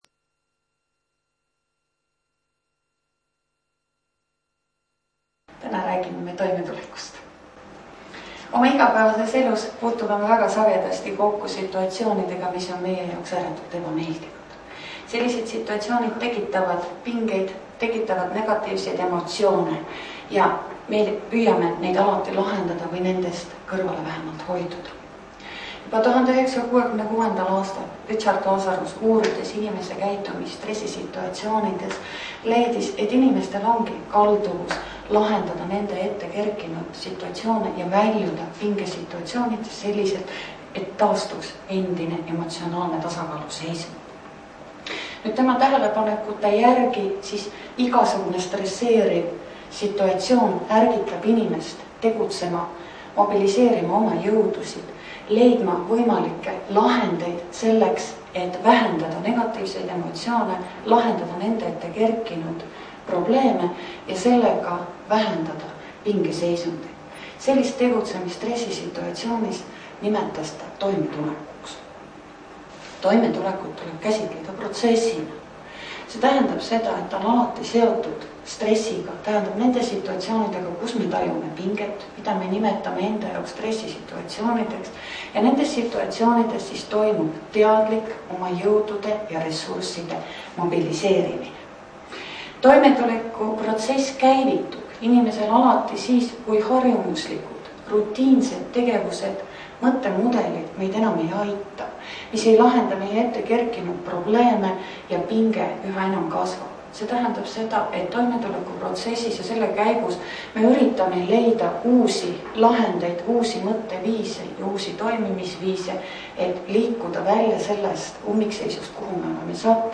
Toimetulek loengu MAPP printimiseks Mapi lugemiseks vajate programmi Adobe Acrobat Reader kui teil seda arvutis ei ole siis leiate selle siit Toimetulek loeng MP3 failina (14MB)